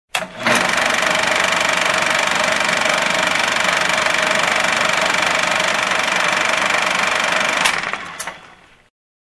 На этой странице собраны звуки кинопроектора в высоком качестве – от мягкого гула до характерных щелчков пленки.
Звук запуска кинопроектора с пленкой